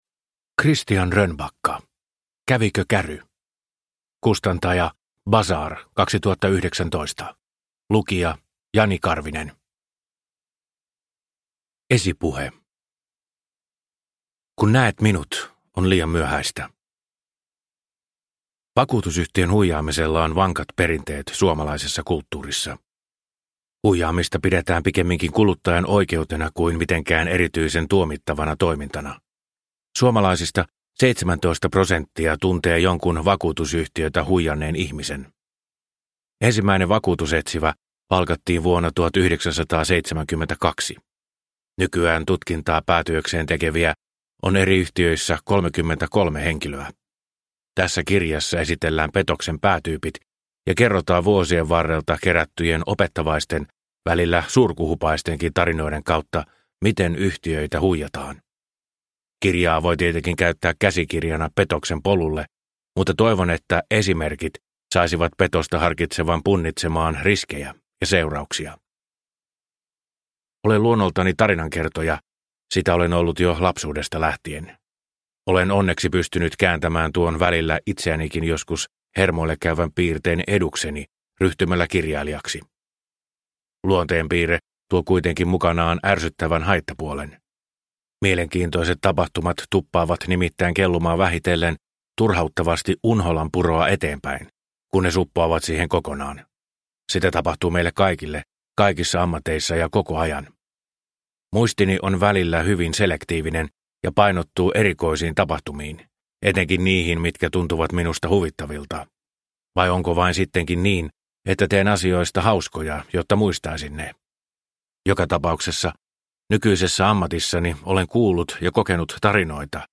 Kävikö käry? – Ljudbok – Laddas ner